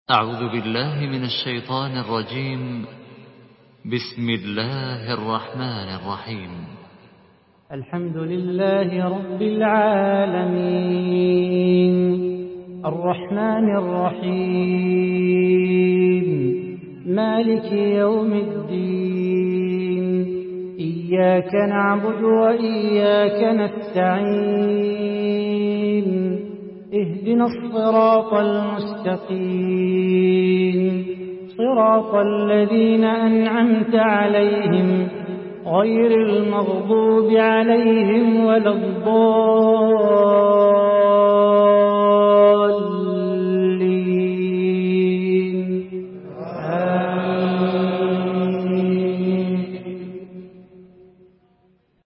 Surah Al-Fatihah MP3 in the Voice of Salah Bukhatir in Hafs Narration
Murattal